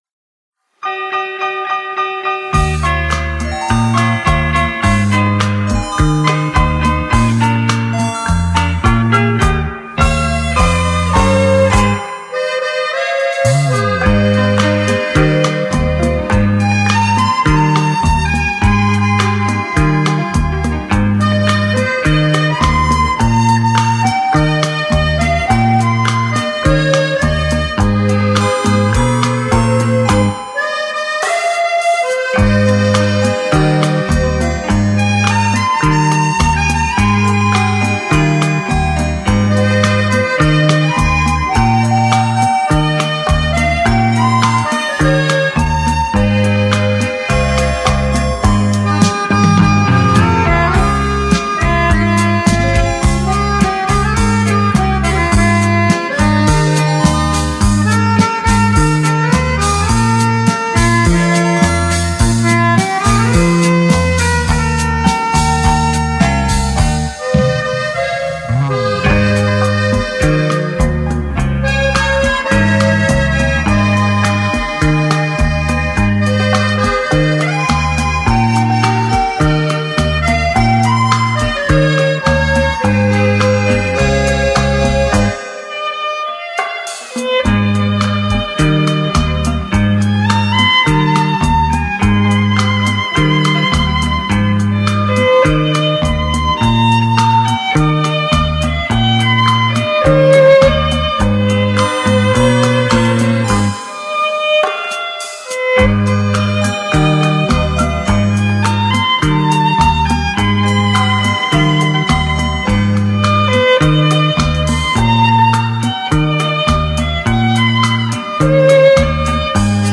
象双钢琴、双电子琴一样的系列，节奏明快，旋律优美动听。